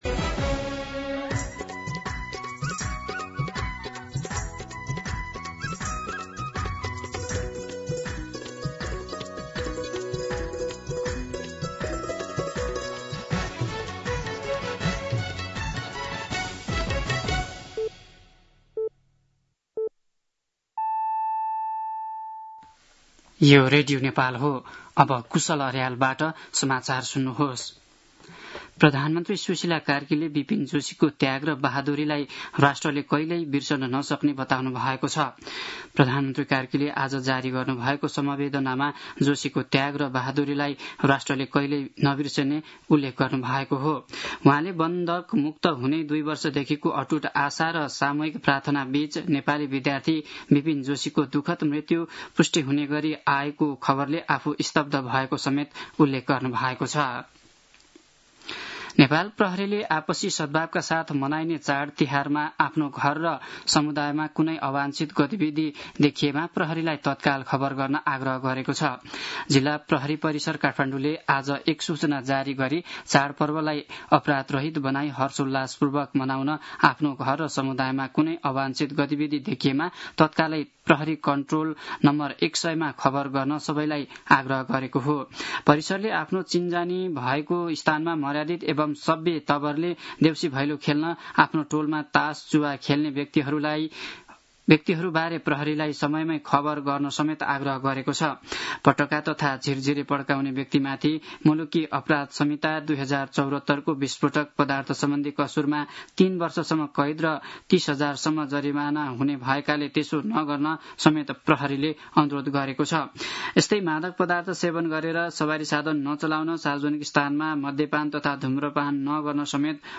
An online outlet of Nepal's national radio broadcaster
दिउँसो ४ बजेको नेपाली समाचार : २९ असोज , २०८२